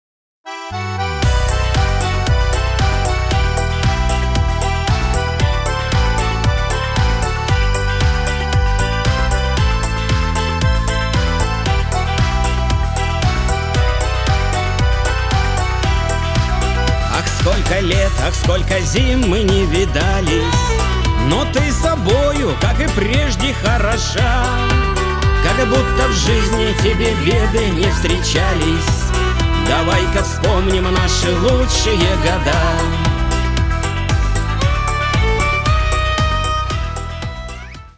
• Качество: 320, Stereo
мужской вокал
женский вокал
скрипка
аккордеон
русский шансон